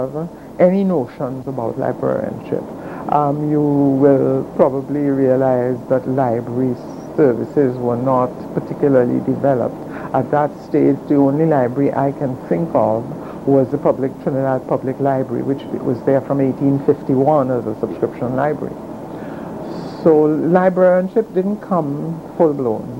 3 audio cassettes